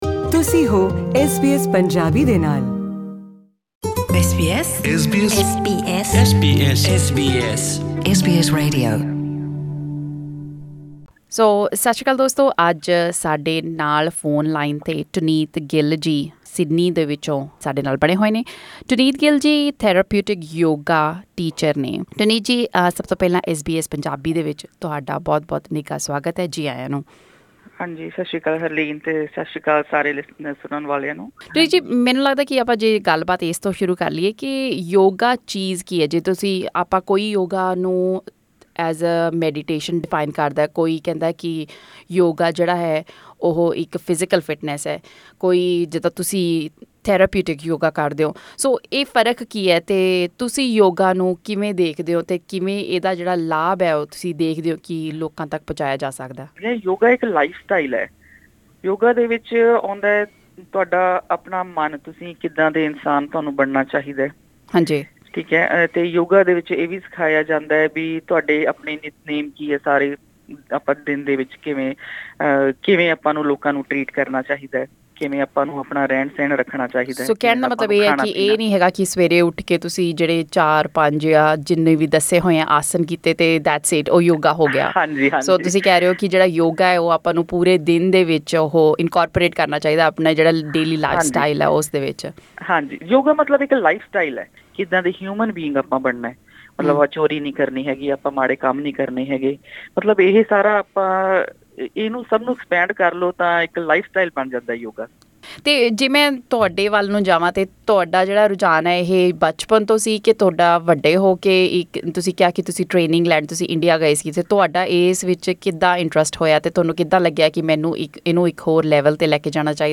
Click on the player at the top of the page to listen to the interview in Punjabi.